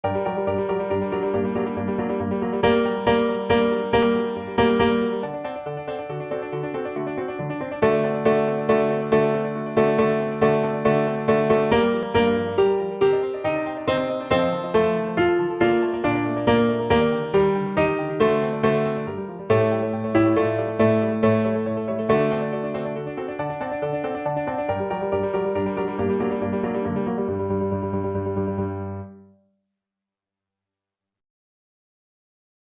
Ténors